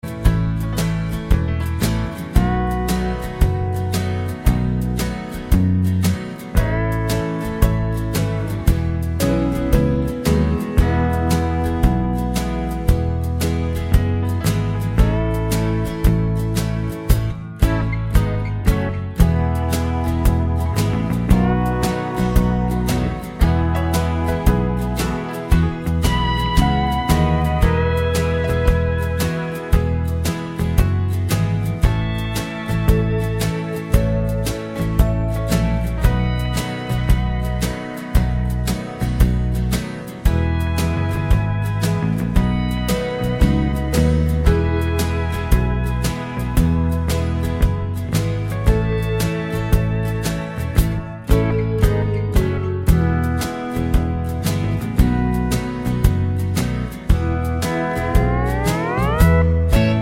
no Backing Vocals Country (Male) 3:20 Buy £1.50